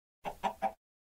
Звуки курицы, Minecraft
В подборке есть клоктанье, испуганные крики и другие характерные звуки этого моба. Отличное качество аудио без посторонних шумов.